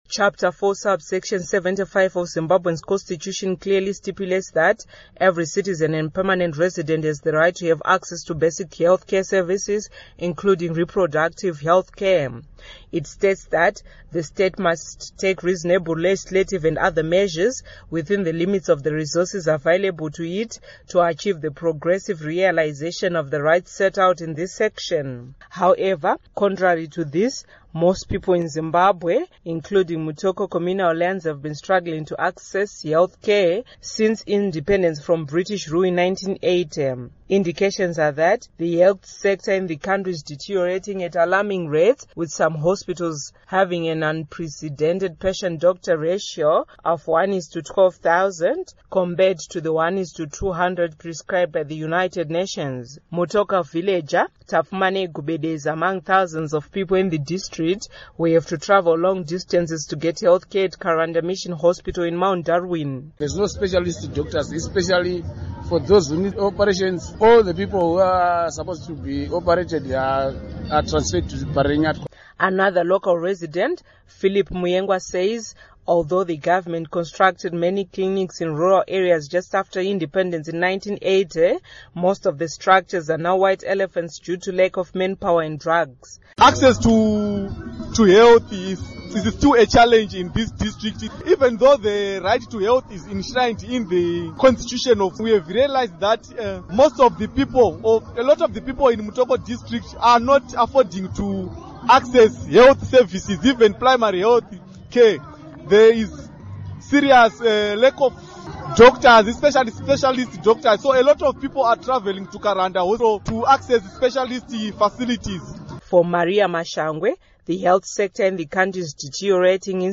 Report on Health Access